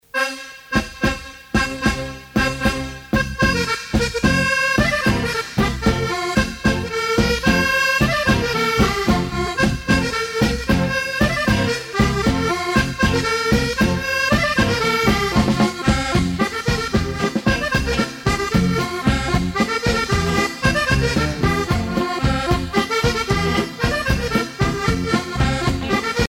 danse : bourree